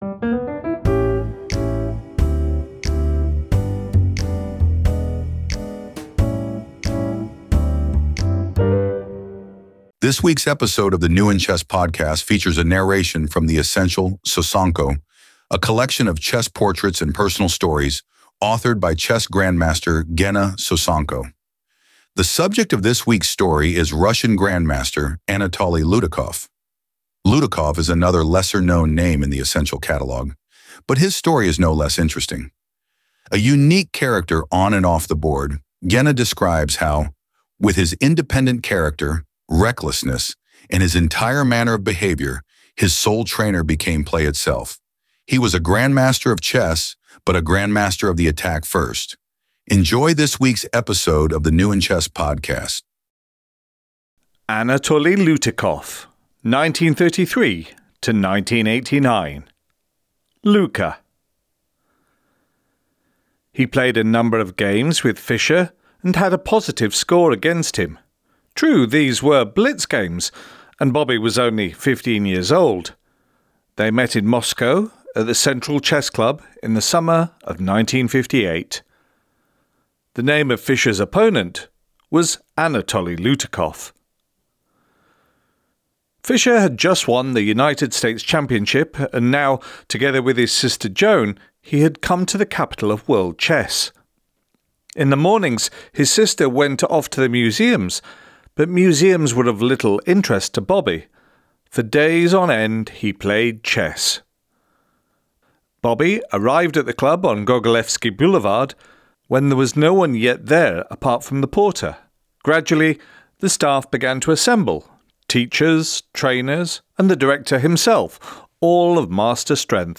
This week's episode of the New In Chess Podcast features a narration from the Essential Sosonko, a collection of chess portraits and personal stories authored by chess grandmaster Ghenna Sosonko. The subject of this week's story is Russian grandmaster Anatoly Lutikov (1933-1989).